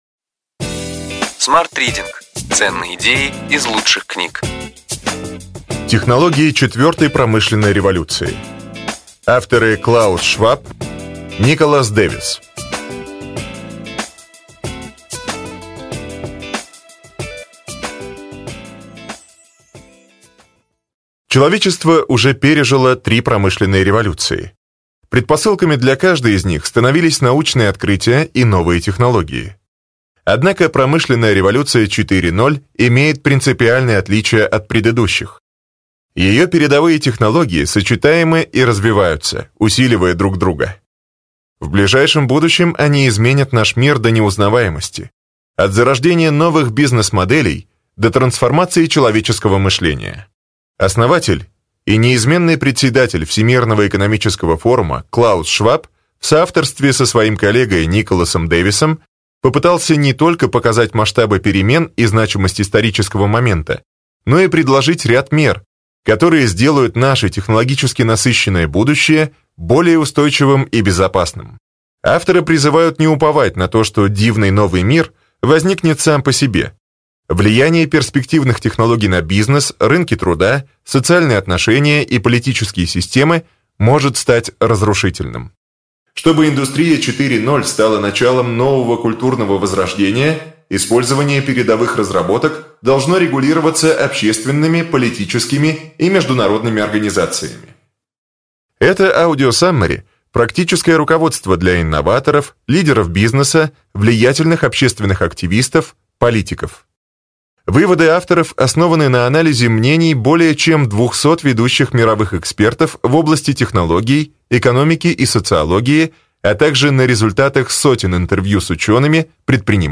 Аудиокнига Ключевые идеи книги: Технологии четвертой промышленной революции.